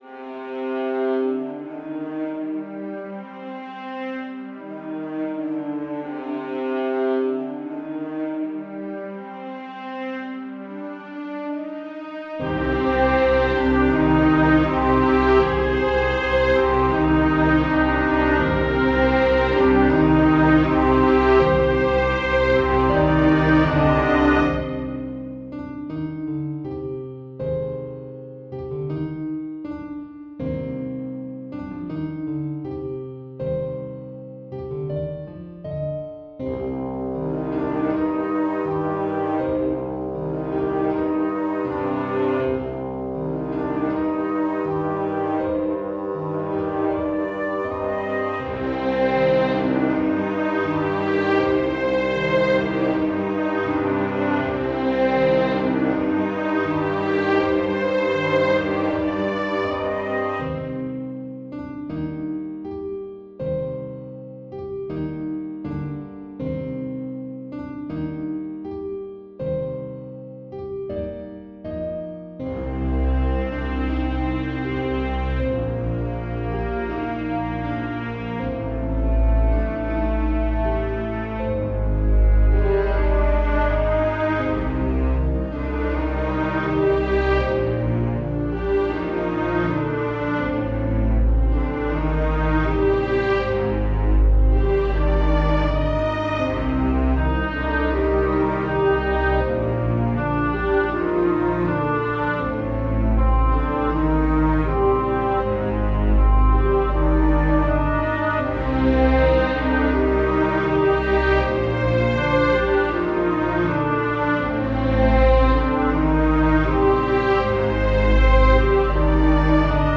However, the last day I started with a melody, harmonized it in like 6 different ways and ended up doing... this (not properly mixed yet).